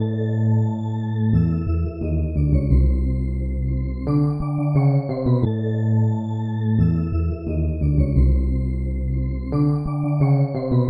东海岸硬朗的旋律 88 Bpm
描述：可以用我的88bpm东海岸鼓，也可以用你自己的。简单，但很热，很有劲
标签： 88 bpm Rap Loops Organ Loops 1.84 MB wav Key : Unknown
声道立体声